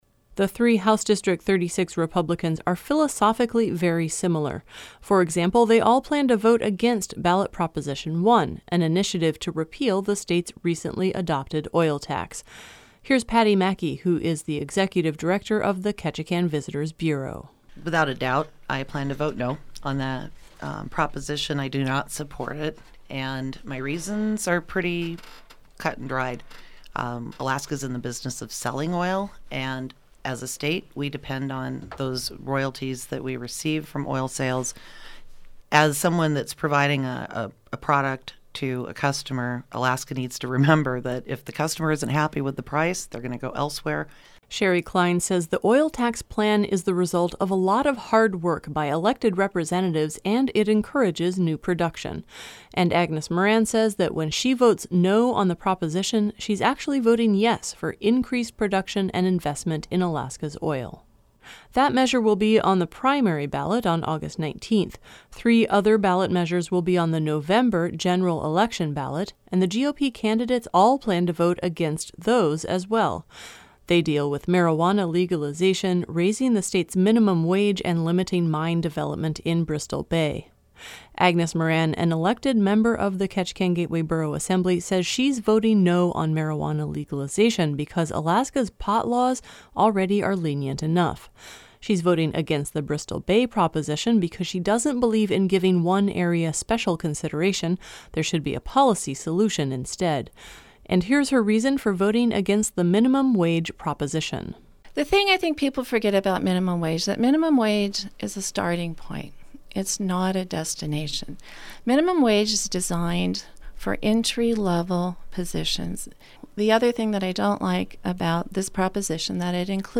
The Republican candidates for House District 36 sat down for a live call-in forum at KRBD on Tuesday.